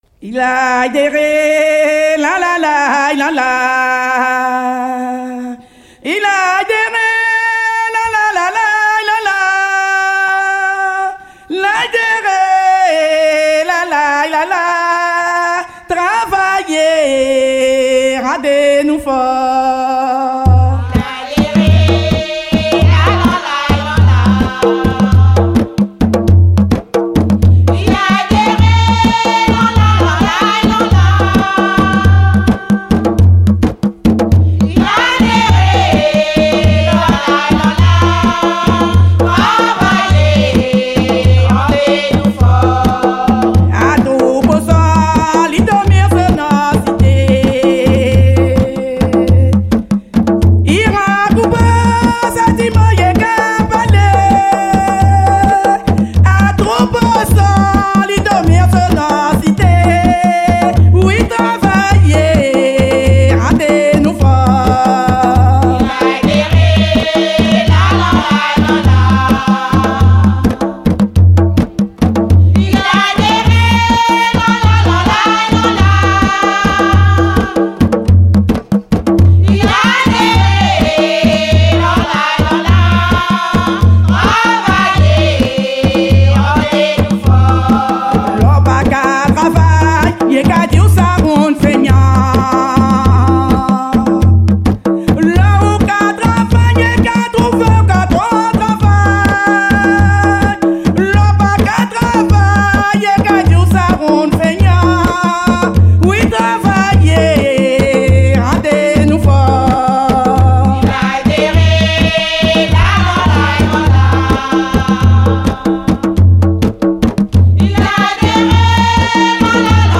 La musique Grajé (écoute en voiture):
Chanson-de-Gragé-écoute-voiture.mp3